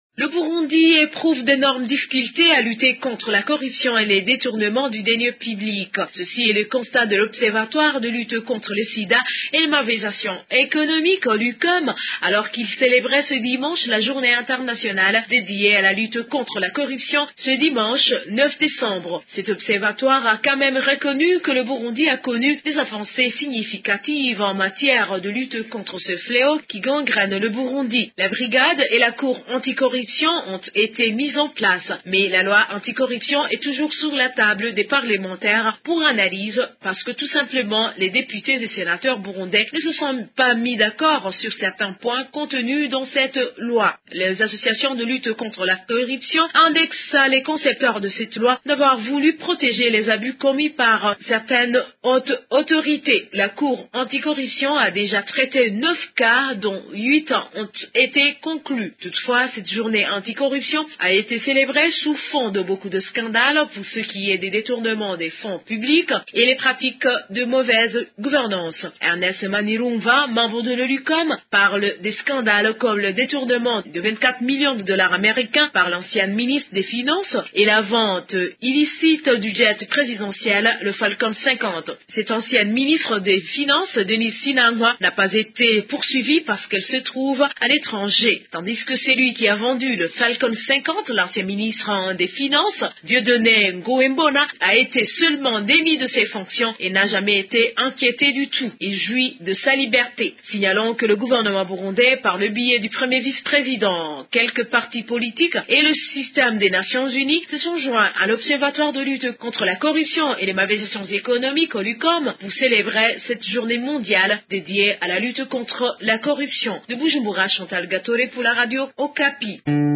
De Bujumbura, une correspondance